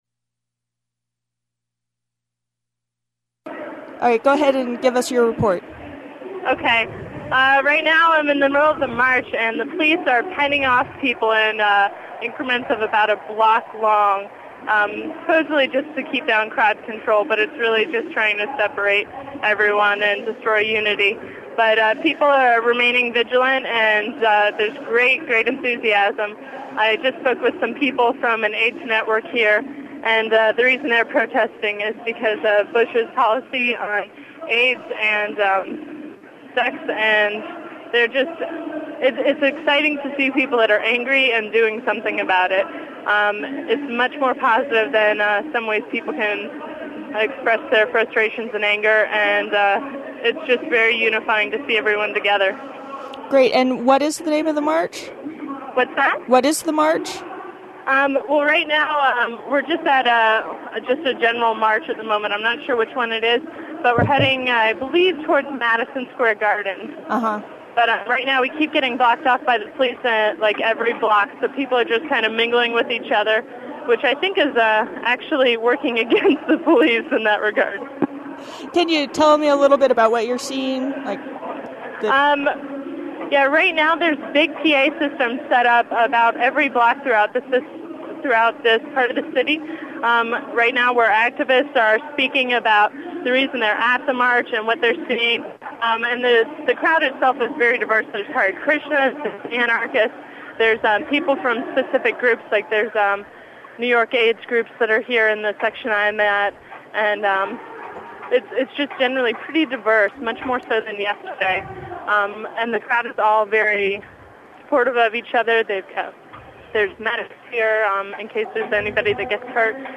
Police breaking up march